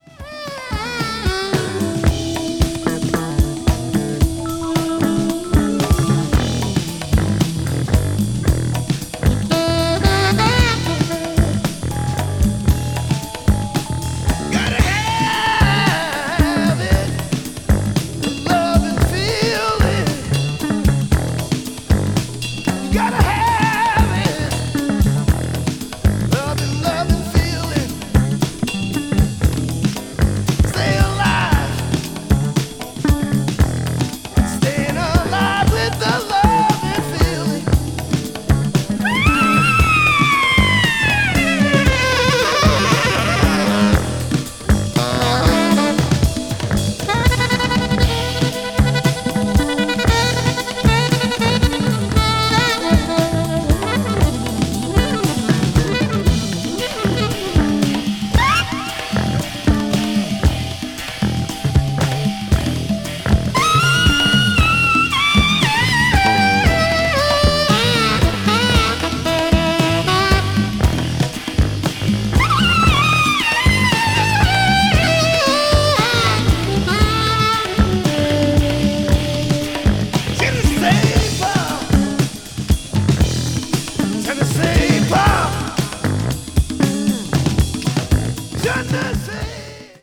中半のフルートのオーガニックな展開もカッコ良過ぎ。